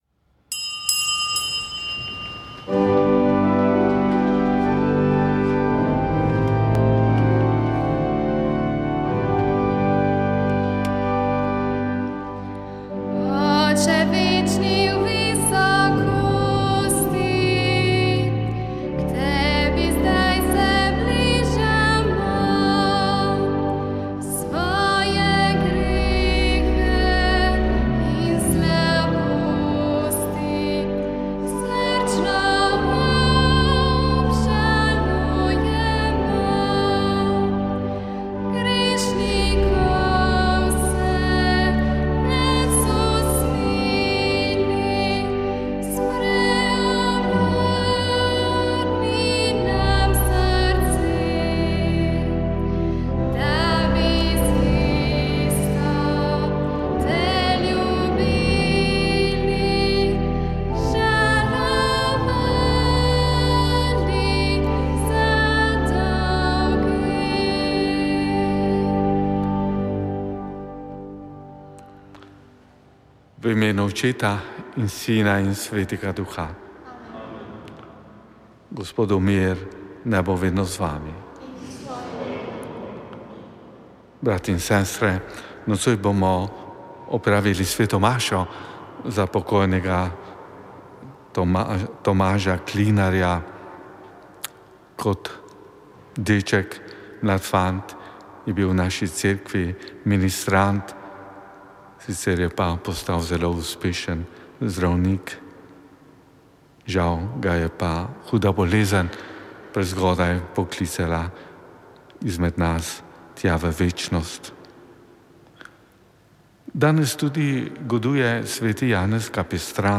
Sv. maša iz cerkve Marijinega oznanjenja na Tromostovju v Ljubljani 23. 10.